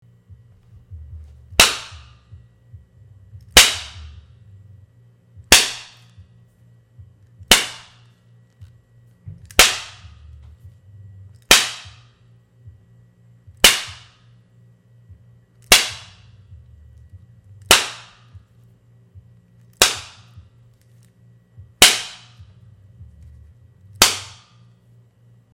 Whip Cracks
Whip Cracks is a popular sound effect that mimics the sharp, impactful snap of a whip.
This short and punchy sound adds energy to transitions and highlights.
whip-cracks-14451.mp3